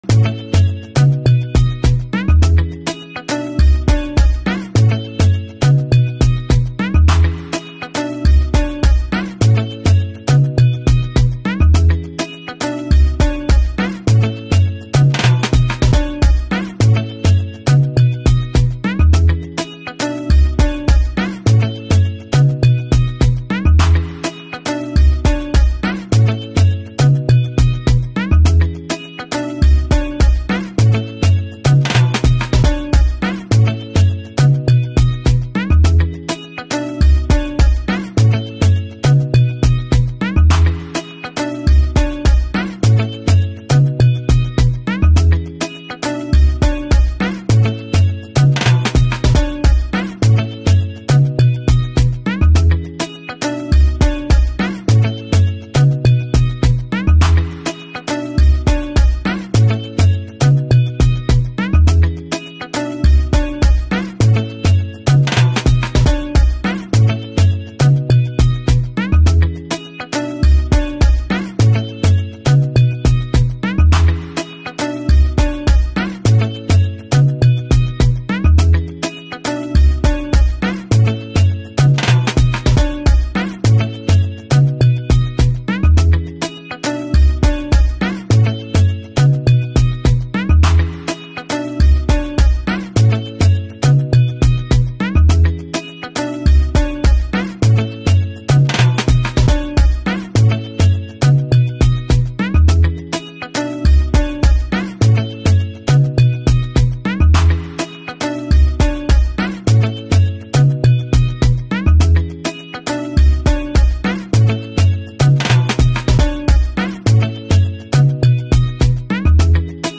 AudioInstrumental
Afro-Pop energy